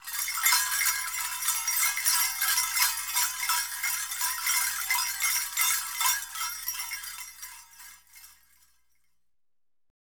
Goat Bells Sound Effect Free Download
Goat Bells